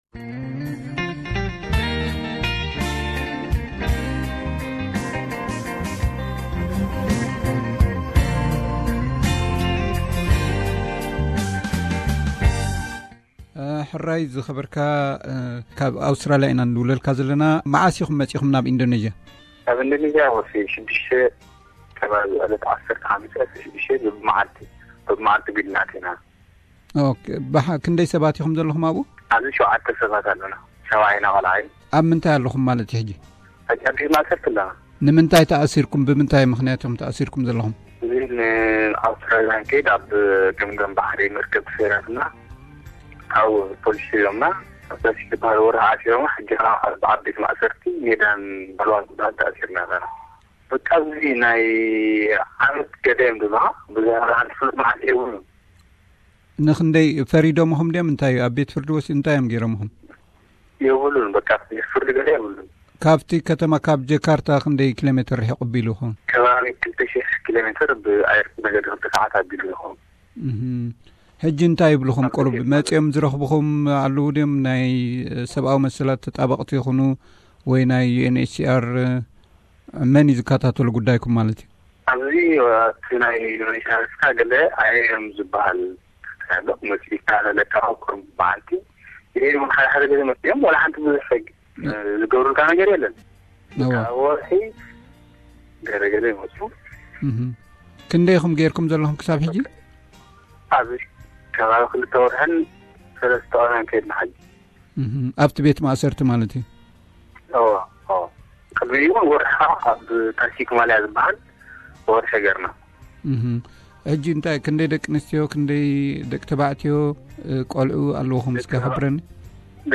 ኤርትራውያን ሓተቲ ዑቕባ ካብ ቤት ማእሰርቲ ኢንዶነዚያ ሓገዝ ይሓቱ ሓደ ቆልዓ ዝርከቦም ሸቡዓተ ኤርትራዊያን ሓተቲ ዑቕባ ኣብ ርሑቕ ደሴት ናይ ኢንዶነዚያ ተኣሲሮም ብዘይፍርዲ ንኣዋርሕ ብዘይዝኾነ ኣቓልቦ ንኣዋርሕ ተኣሲሮም፣ መጻኢኦም እንታይ ሙዃኑ ከይፈለጡ ይነብሩ ኣለዉ፡ ገለ ሓገዝ እንተረኸቡ ሓደ ካብኦም ምስ ራዲዮ SBS ቋንቋ ትግርኛ ሽግሮም ኣካፊሉ ኣሎ።